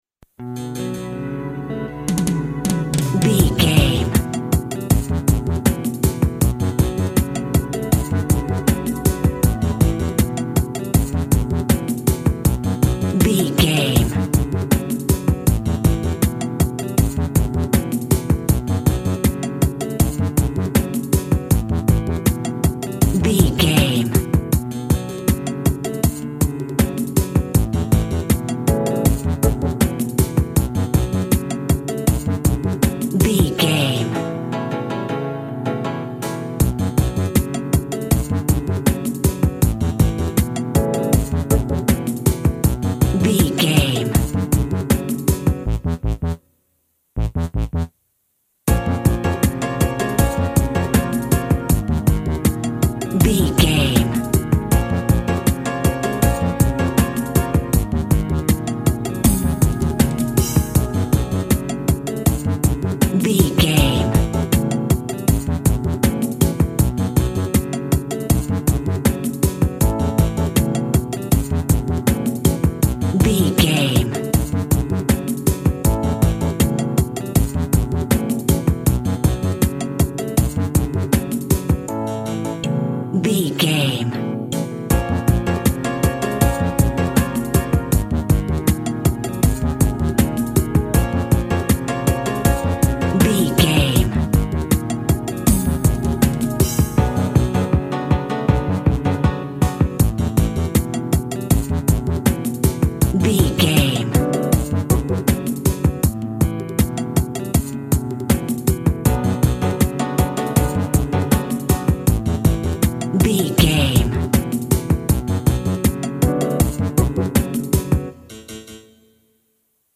Also with small elements of Dub and Rasta music.
Aeolian/Minor
tropical
drums
bass
guitar
steel drum